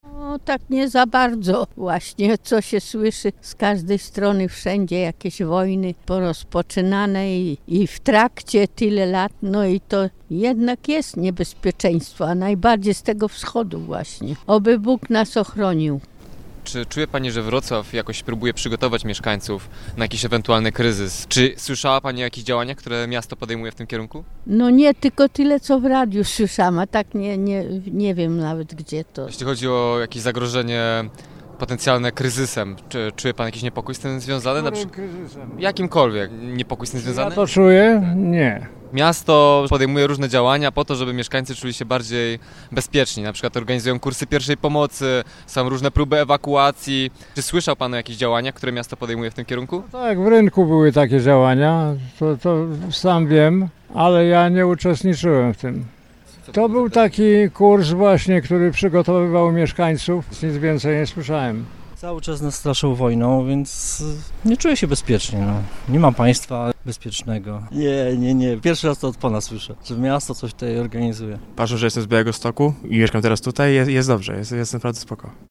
Pytamy mieszkańców Wrocławia, czy czują się bezpiecznie.
sonda_czy-wroclawianie-czuja-sie-bezpiecznie.mp3